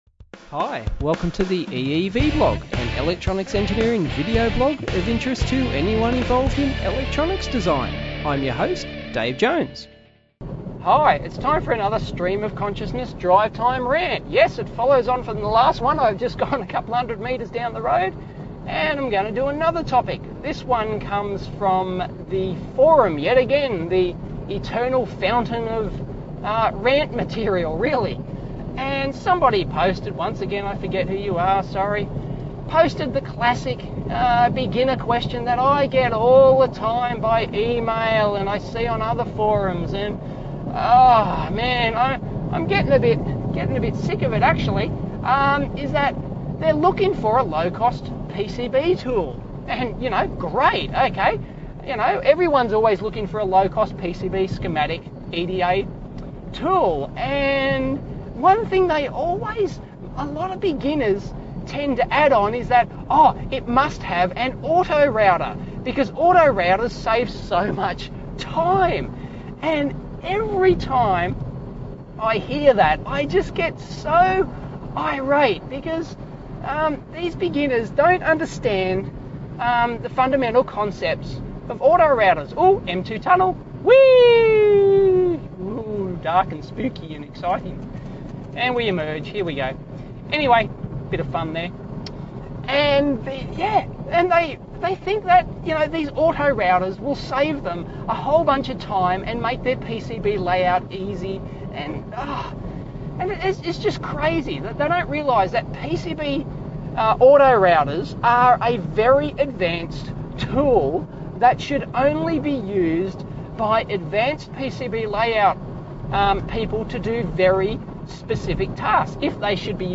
Drive time rant again.